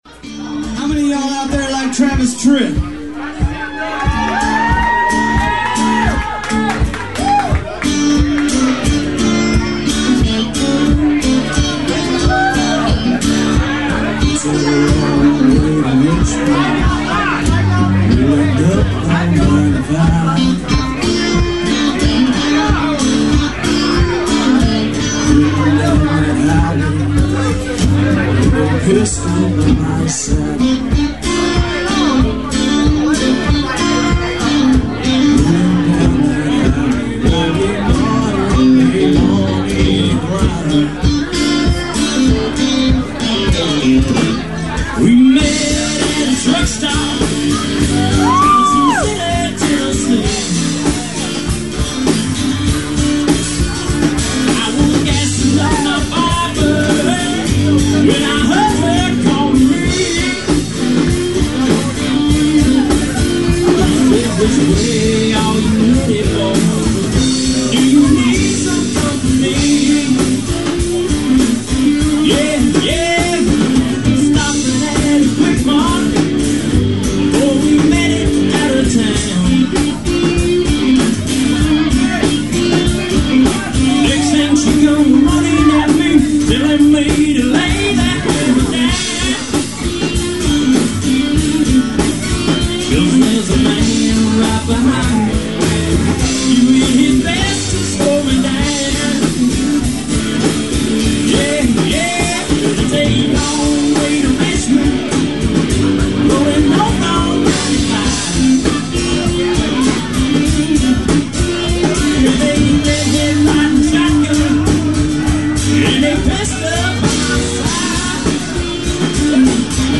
Bonnie and Clyde in Missouri